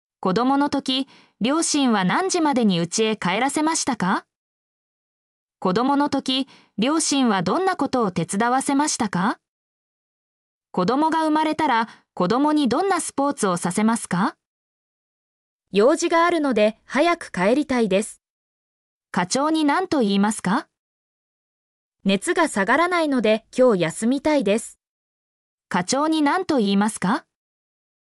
mp3-output-ttsfreedotcom-7_iDqxKab9.mp3